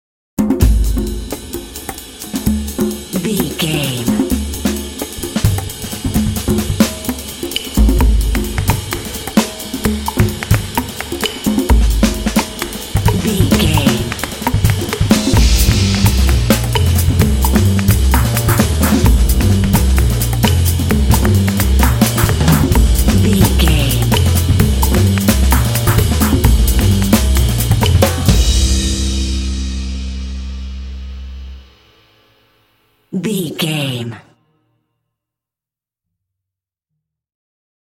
Mixolydian
groovy
double bass
jazz drums